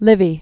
(lĭvē) Originally Titus Livius. 59 BC-AD 17.